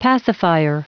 Prononciation du mot pacifier en anglais (fichier audio)
Prononciation du mot : pacifier